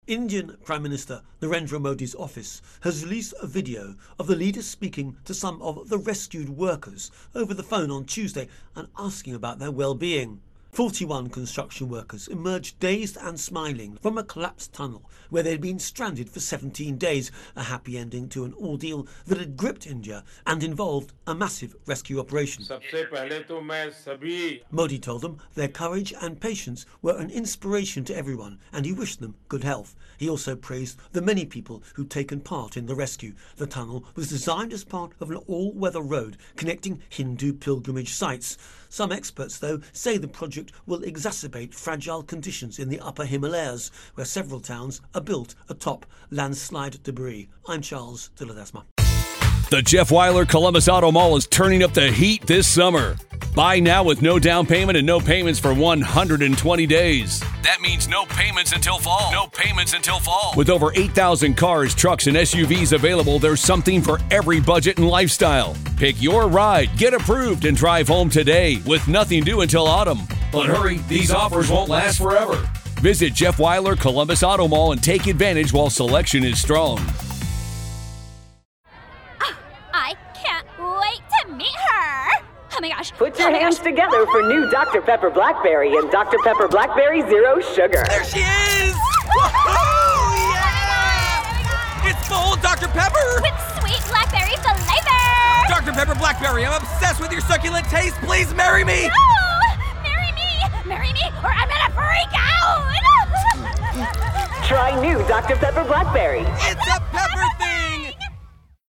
Indian Prime Minister Narendra Modi speaks to rescued construction workers